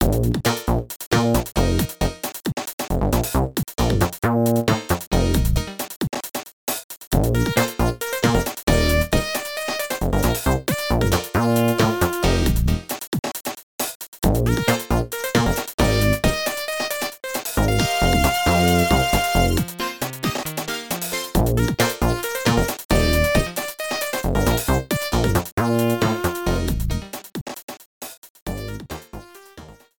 Cropped to 30 seconds, fade out added